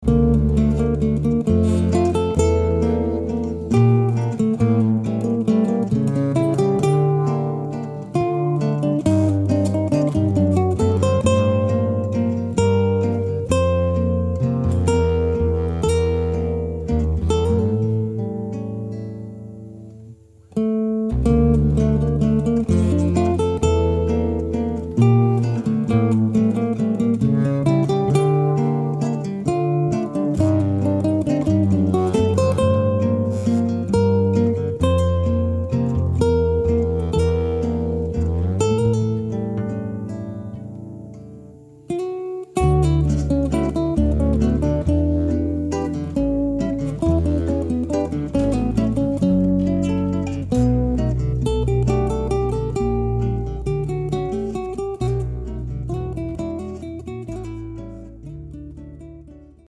Un très joli thème à cinq temps